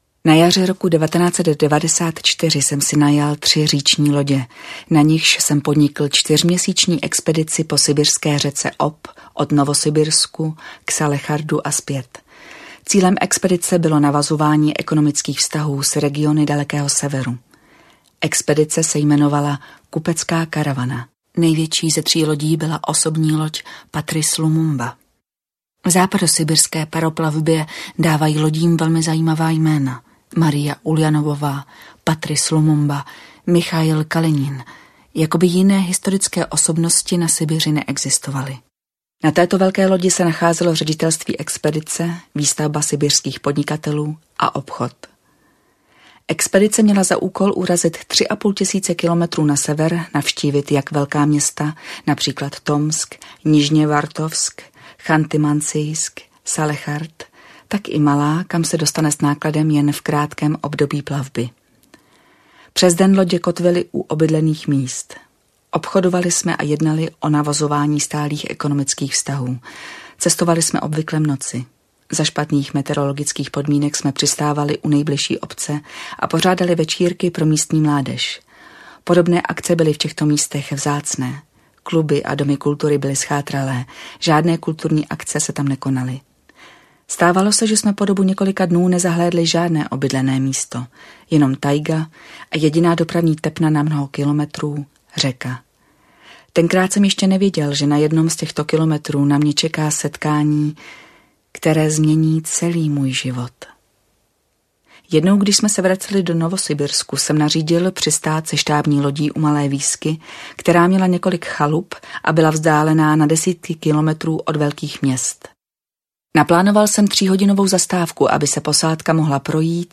Anastasia audiokniha
Ukázka z knihy